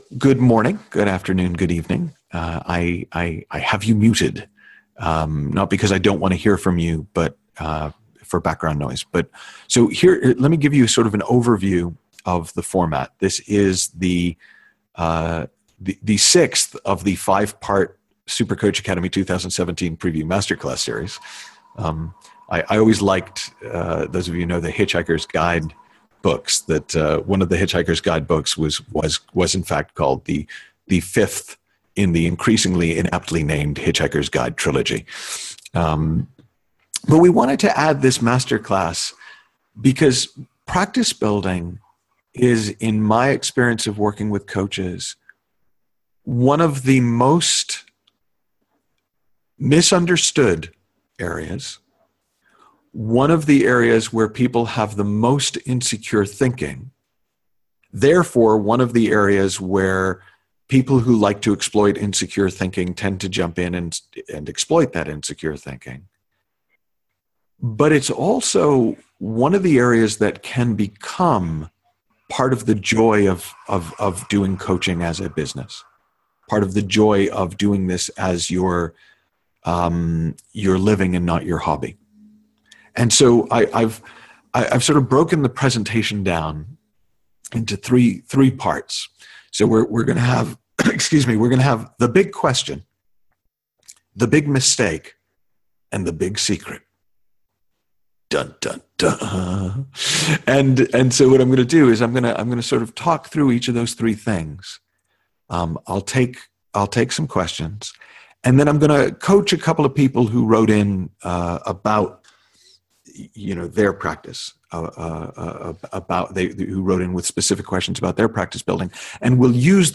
During the webinar, he shared: